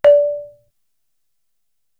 Index of /90_sSampleCDs/Best Service ProSamples vol.55 - Retro Sampler [AKAI] 1CD/Partition D/GAMELAN